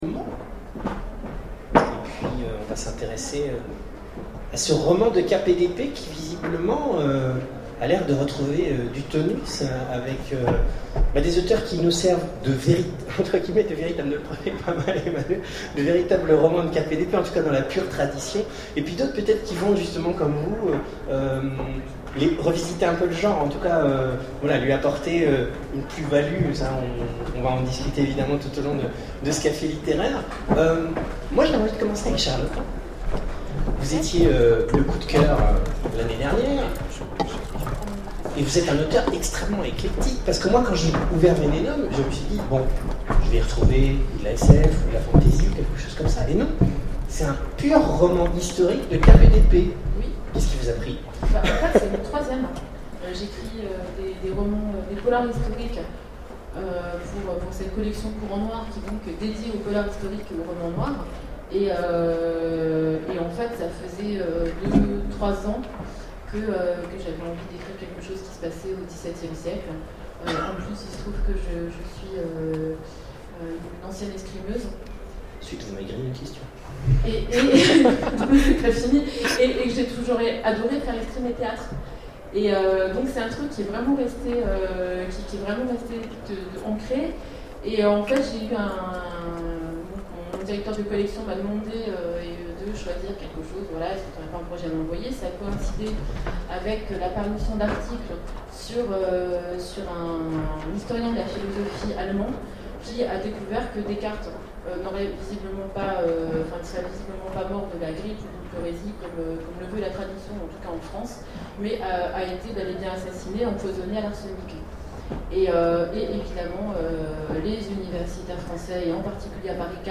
Imaginales 2012 : Conférence De cape et d'épée
Conférence